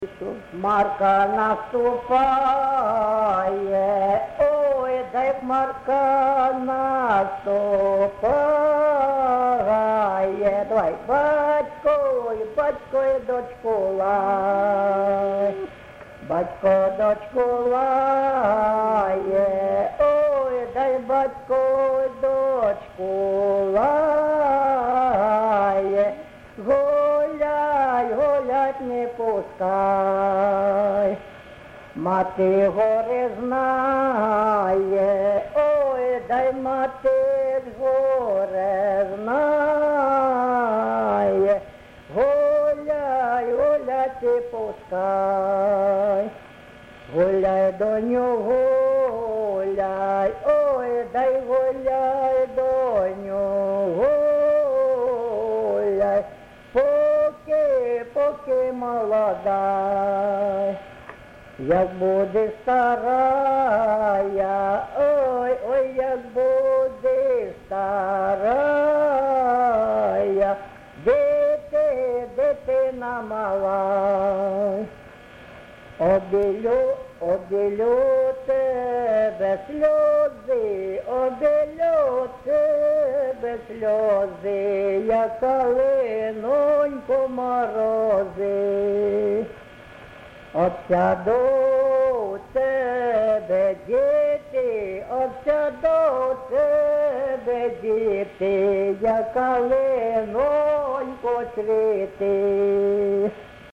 ЖанрПісні з особистого та родинного життя
Місце записум. Антрацит, Ровеньківський район, Луганська обл., Україна, Слобожанщина